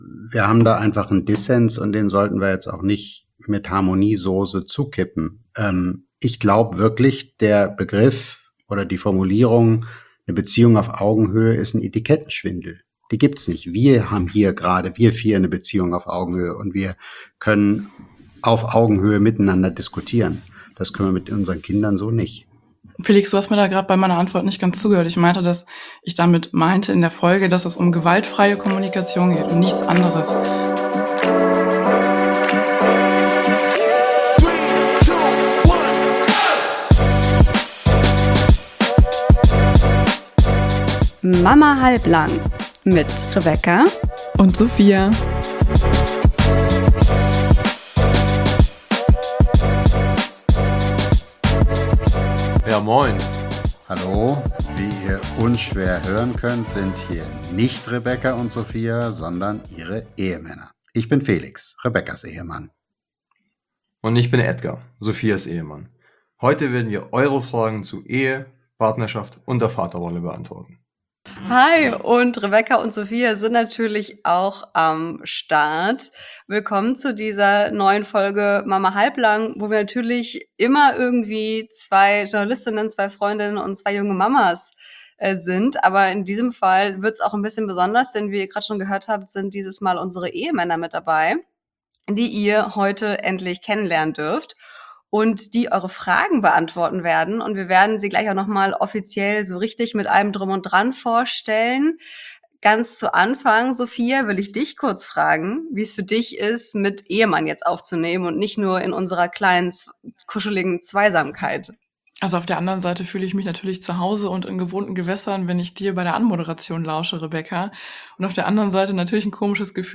Doch jetzt schlafen endlich beide Kinder, wir haben uns zu viert vor die Mikros gehockt und EURE Fragen beantwortet.
Denn bei Erziehungsfragen scheiden sich plötzlich unsere Geister und wir liefern uns einen astreinen Schlagabtausch.